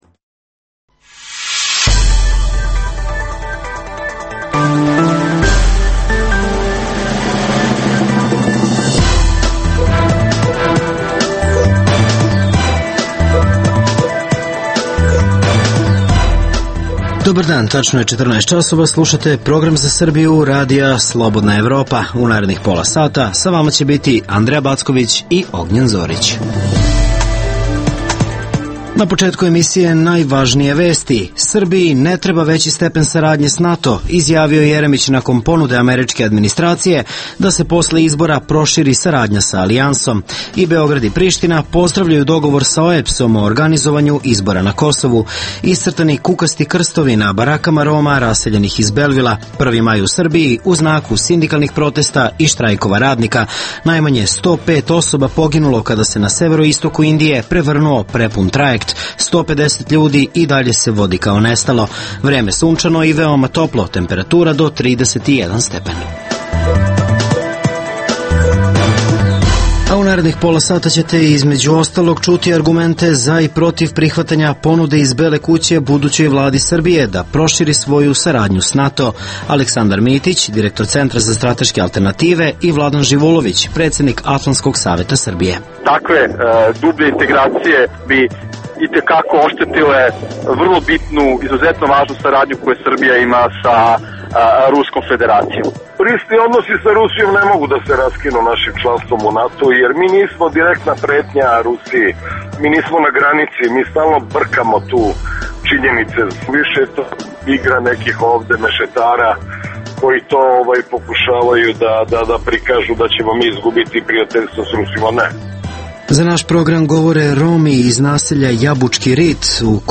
Za naš program govore stanovnici romskog naselja u Jabučkom Ritu.